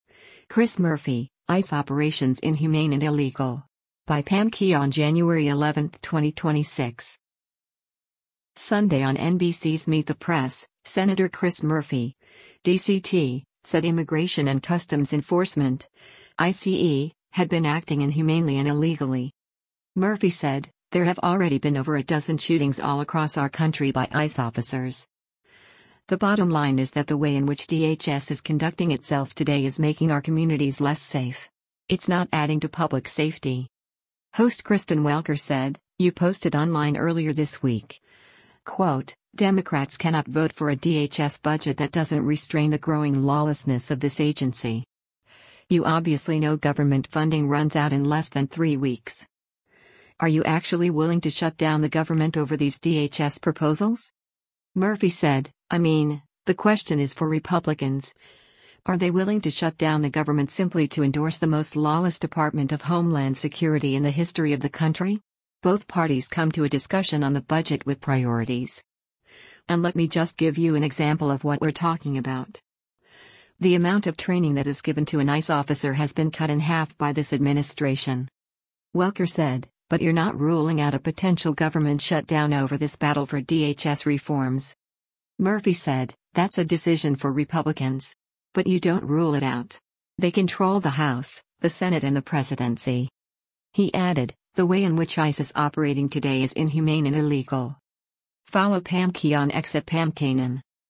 Sunday on NBC’s “Meet the Press,” Sen. Chris Murphy (D-CT) said Immigration and Customs Enforcement (ICE) had been acting inhumanely and illegally.